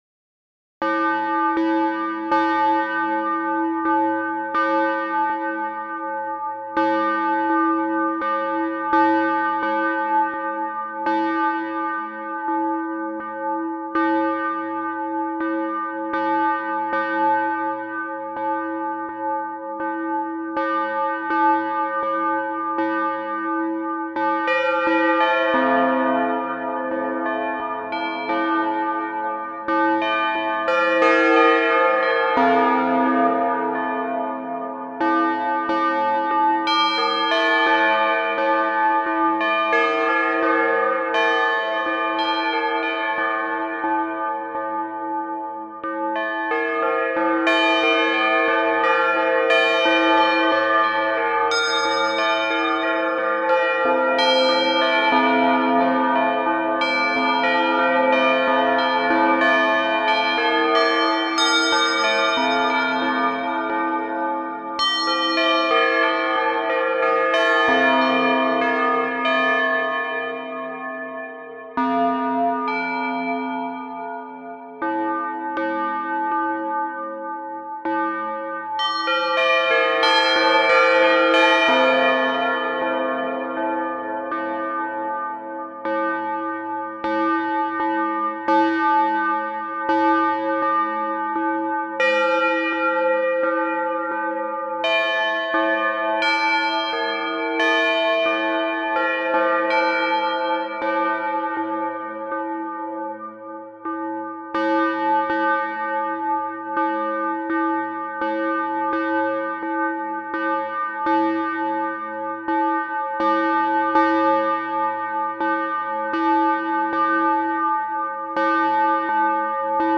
bellsDiva.mp3